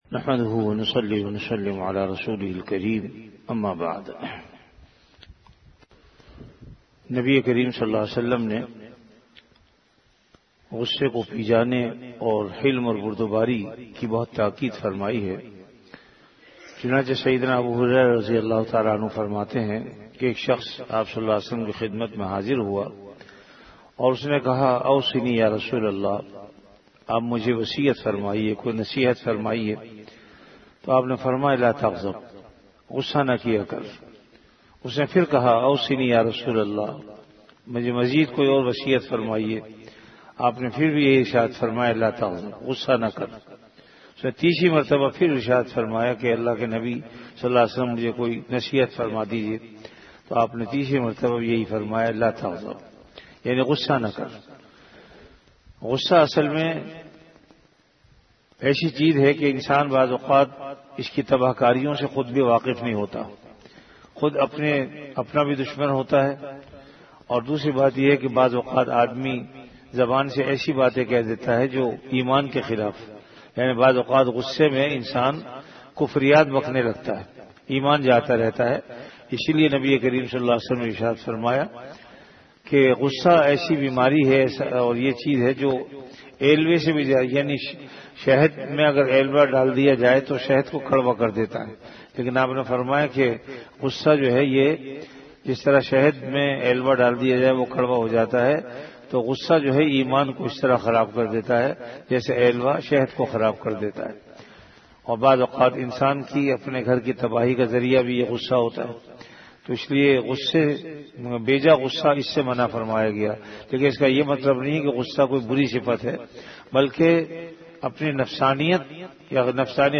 CategoryRamadan - Dars-e-Hadees
VenueJamia Masjid Bait-ul-Mukkaram, Karachi
Event / TimeAfter Fajr Prayer